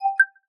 11. notification5